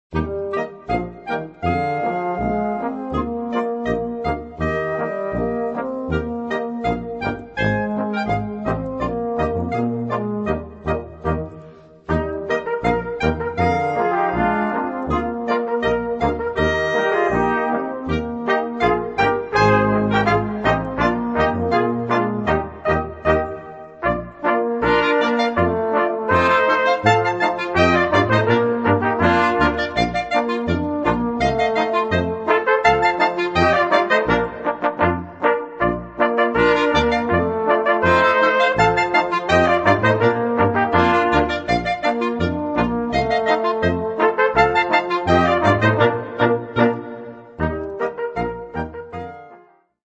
Gattung: für gemischtes Ensemble
Besetzung: Ensemble gemischt